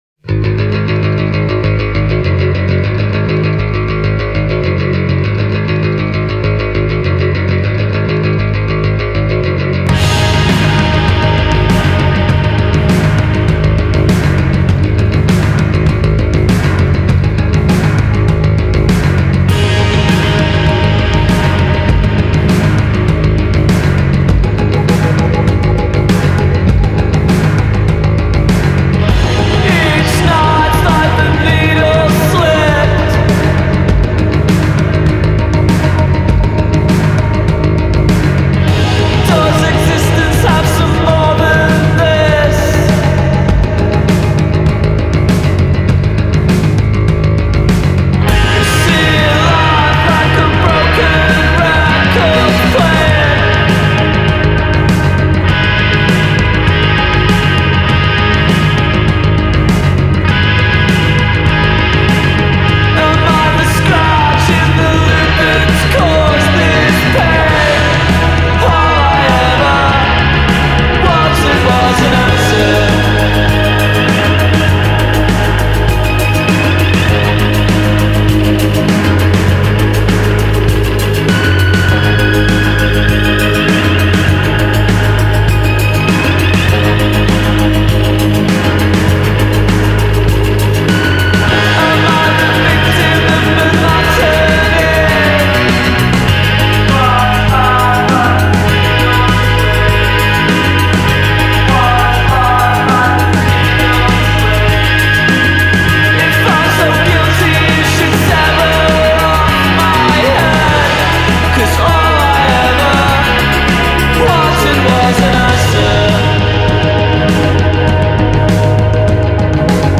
thrilling us with their darkly aggressive post-punk sound.
more melodic intent
bassist
drummer
Guitarists
forming a gauzy haze rather than a barbed one